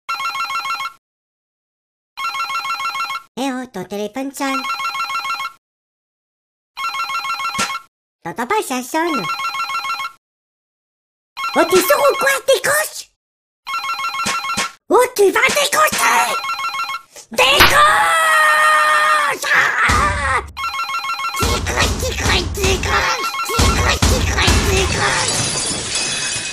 Effets Sonores ,Samsung ,SMS